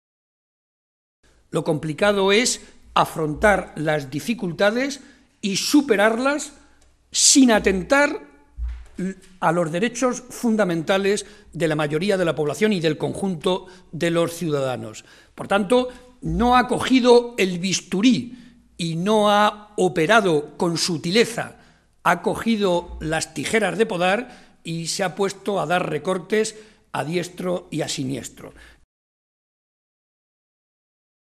José María Barreda, secretario General del PSOE de Castilla-La Mancha
Cortes de audio de la rueda de prensa